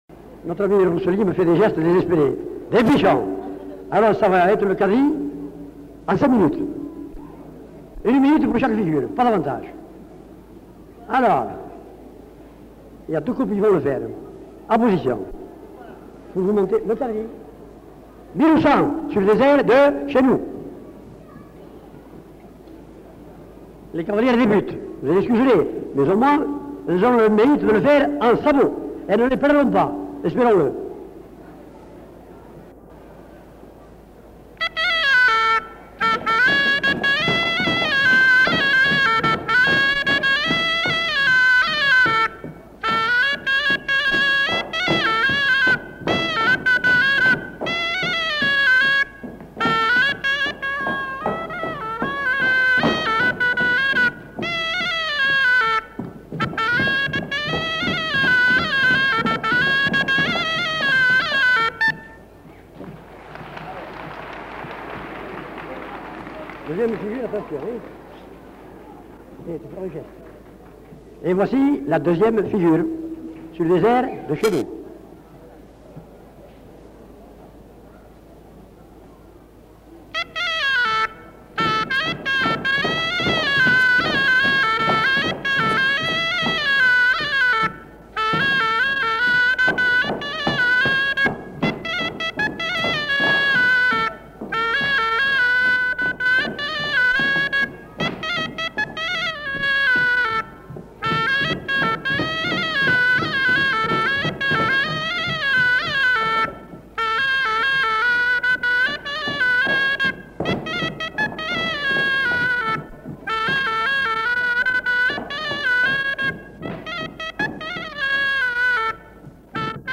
Quadrille